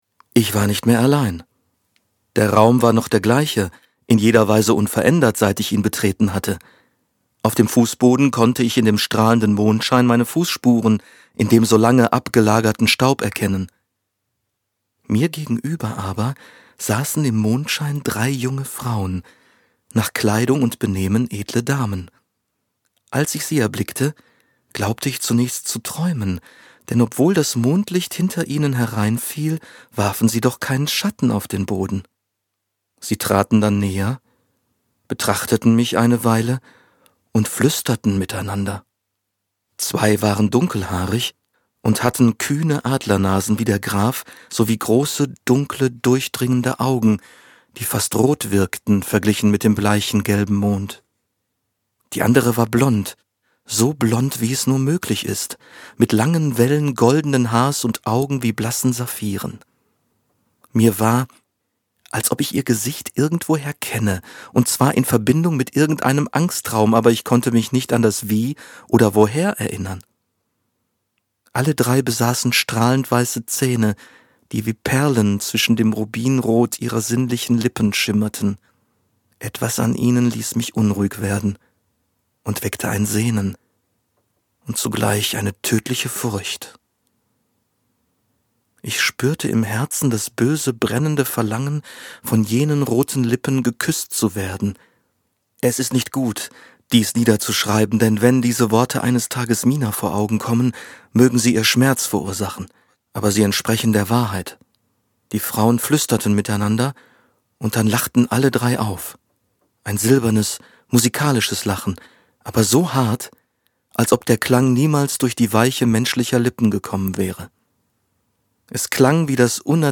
Synchronausschnitt